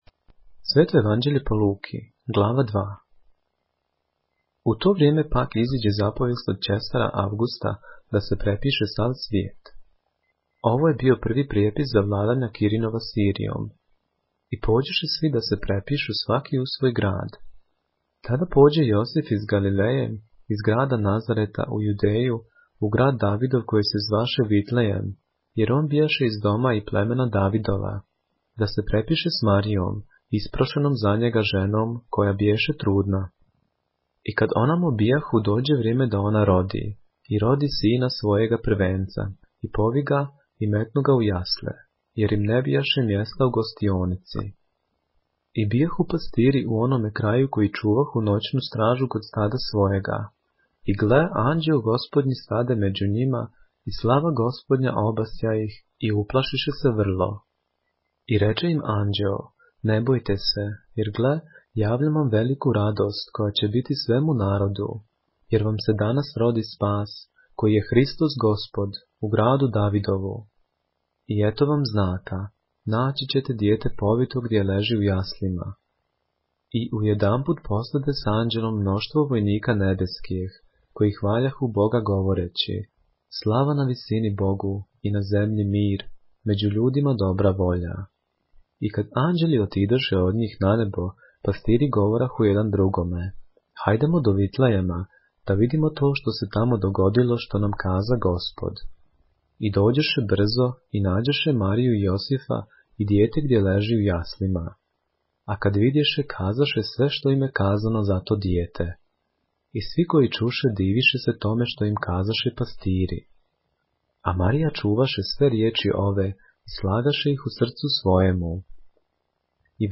поглавље српске Библије - са аудио нарације - Luke, chapter 2 of the Holy Bible in the Serbian language